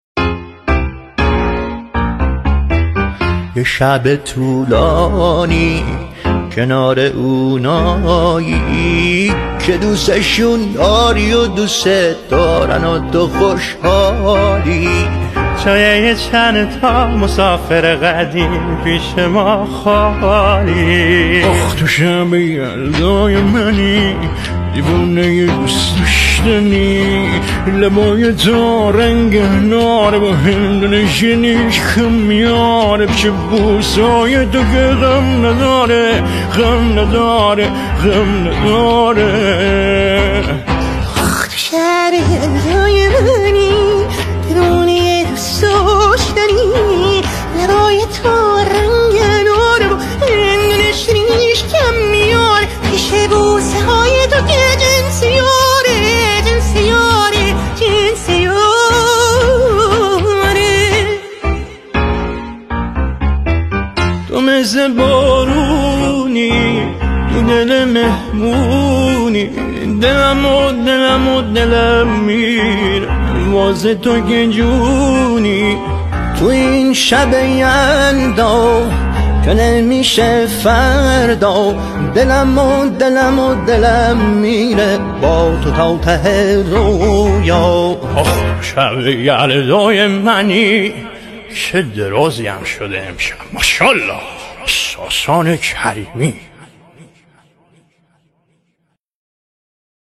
میکس
تقلید صدا